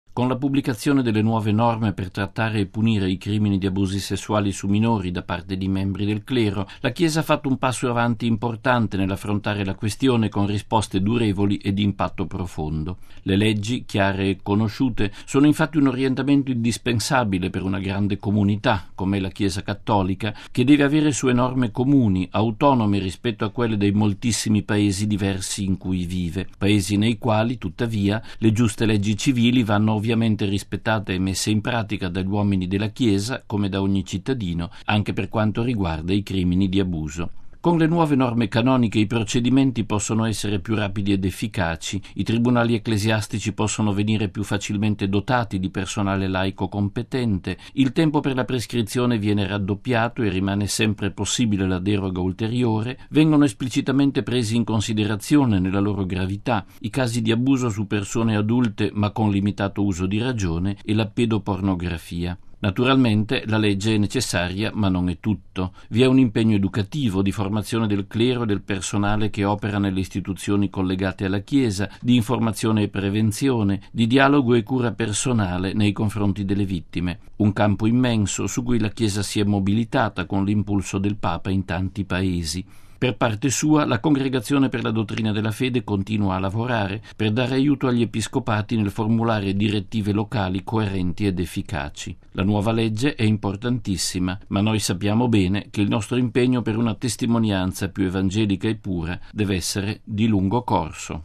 Si tratta – spiega il nostro direttore generale, padre Federico Lombardi in questo editoriale per Octava Dies, il settimanale informativo del Centro Televisivo Vaticano – di un lungo percorso intrapreso dalla Chiesa sulla strada non solo di una grande purificazione, ma anche di un rinnovato slancio formativo: